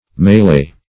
Mel'ee \M[^e]`l['e]e"\ (m[asl]`l[asl]"), n. [F., fr. m[^e]ler to